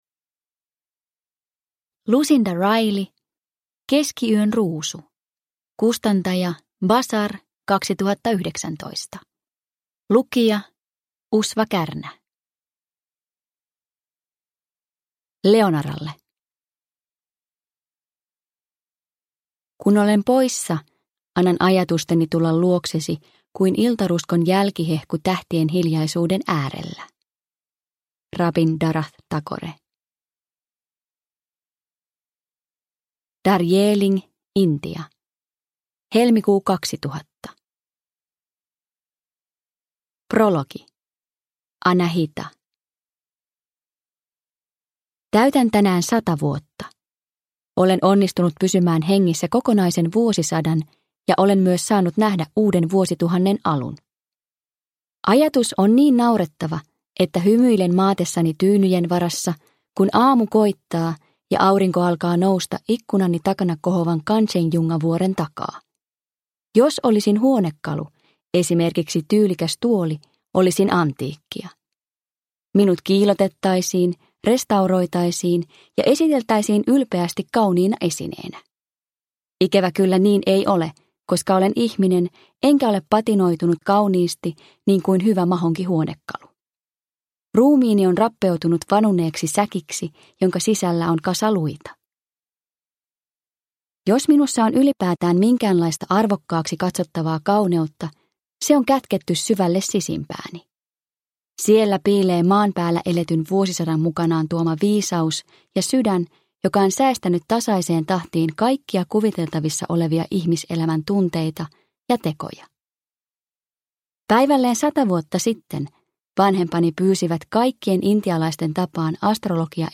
Keskiyön ruusu – Ljudbok – Laddas ner